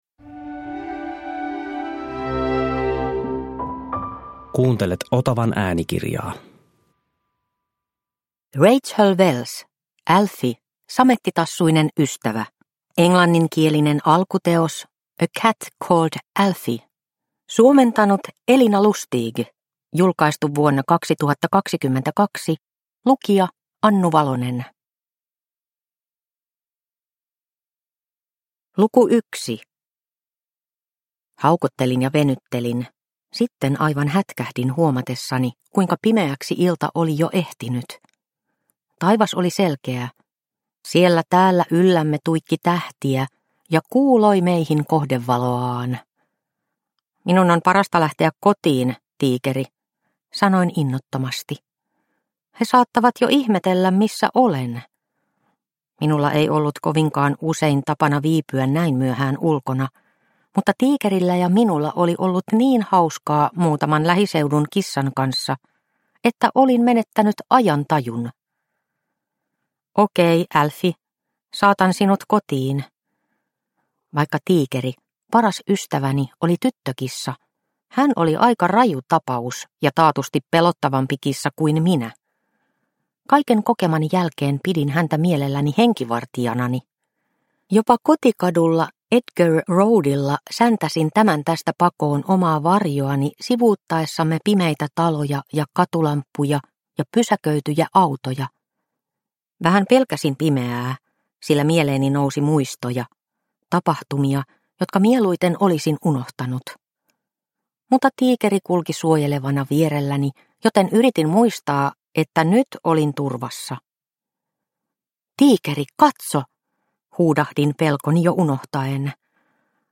Alfie - samettitassuinen ystävä – Ljudbok – Laddas ner